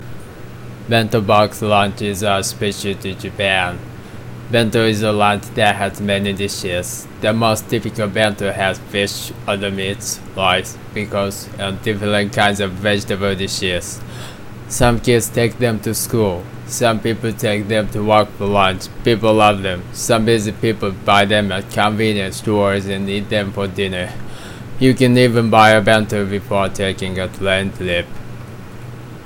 BENTO BOX (15 10 13) I think I did it about deep voice